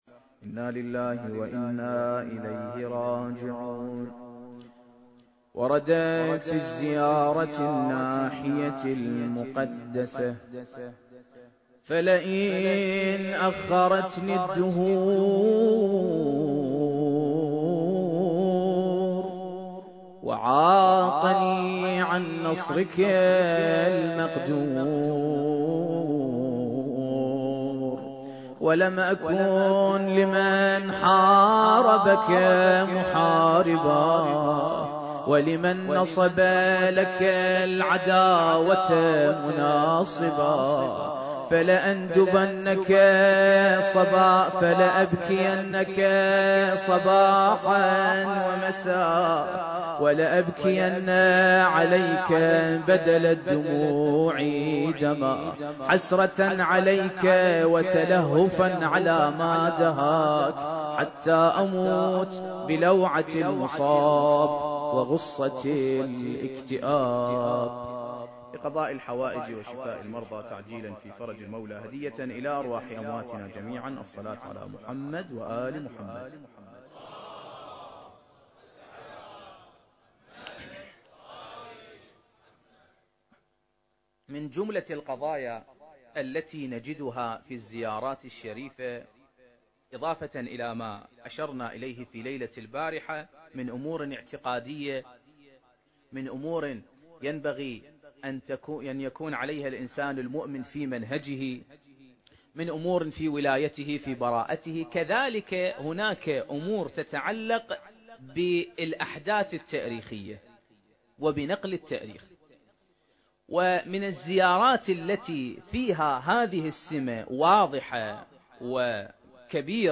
المكان: مسجد النبي محمد (صلّى الله عليه وآله وسلم)/ الشارقة التاريخ: 2024